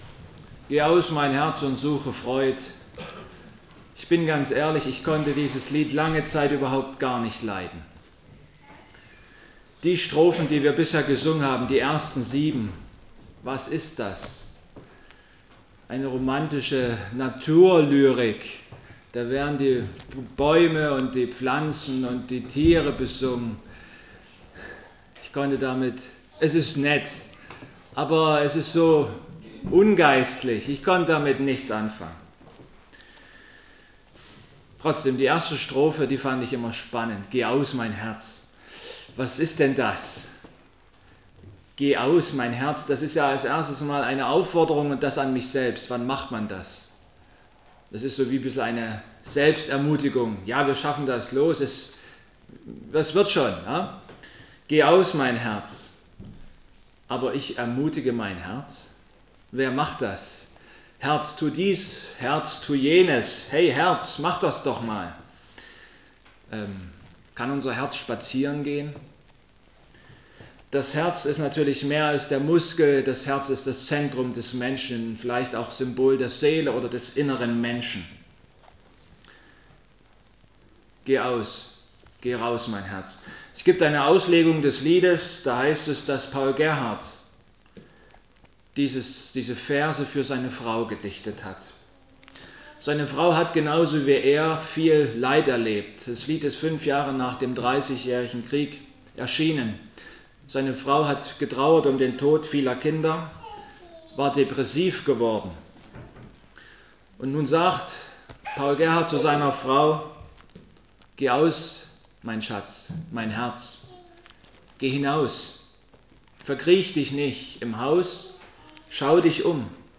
Gottesdienstart: Erntedankgottesdienst